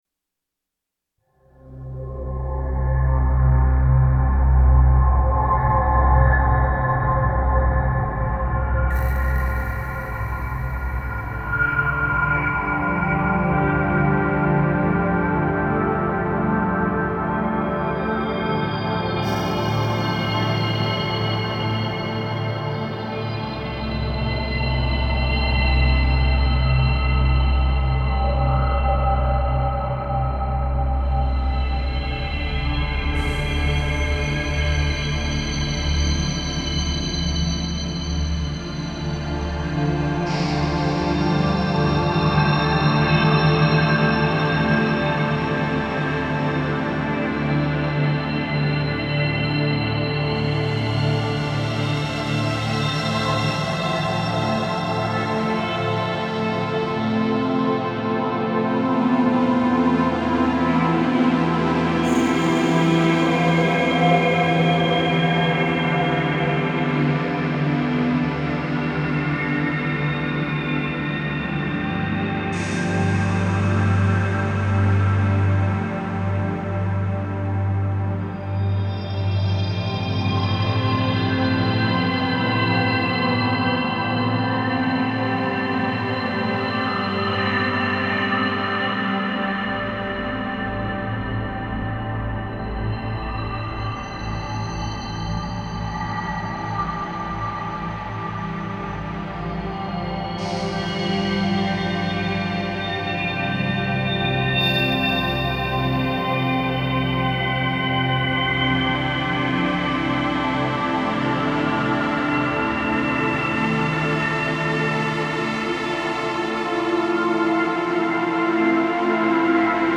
Genre: Dark Ambient, Drone.